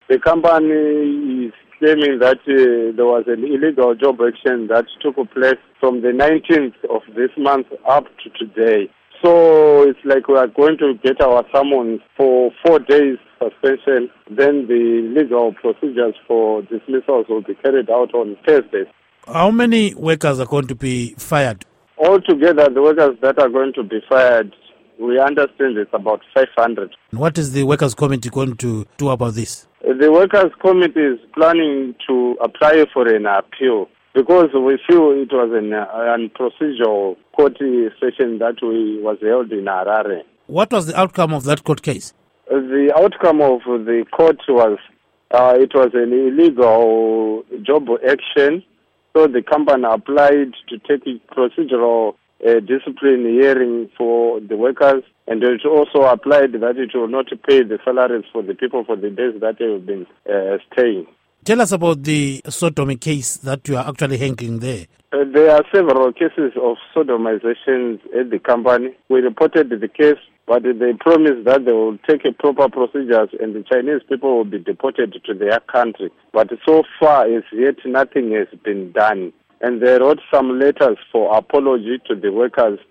Interview With Anjin Worker